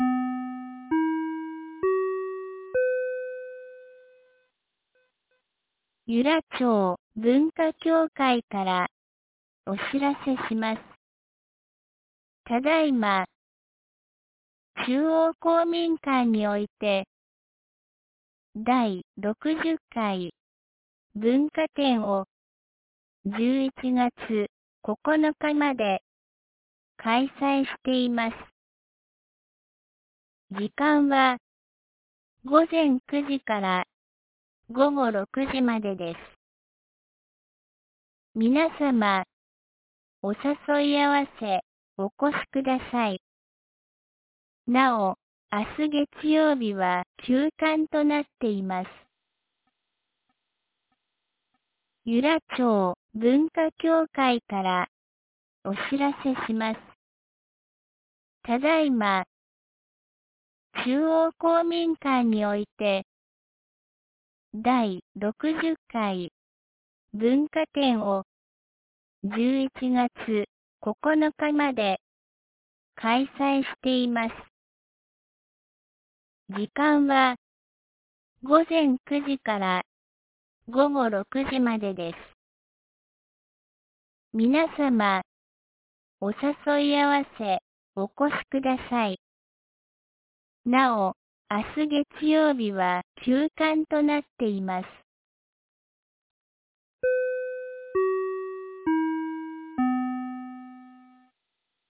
2025年11月02日 12時22分に、由良町から全地区へ放送がありました。